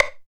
87 STICK  -R.wav